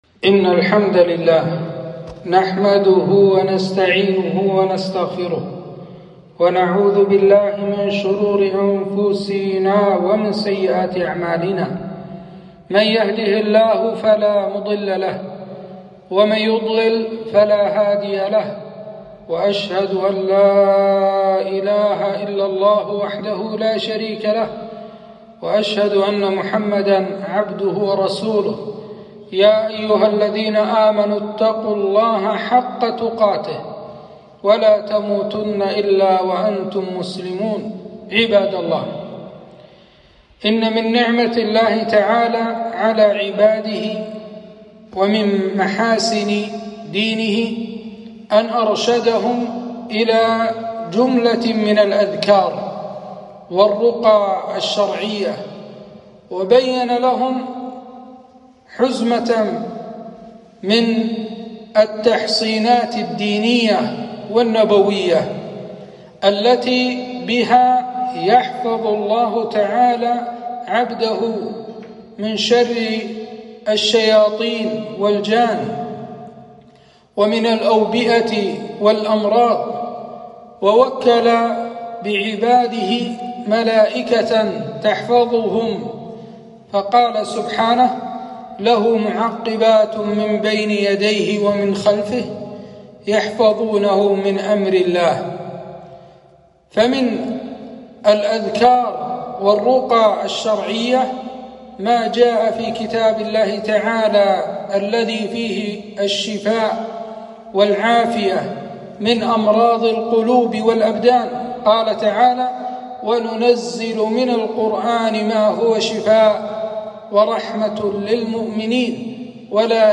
خطبة - الرقية الشرعية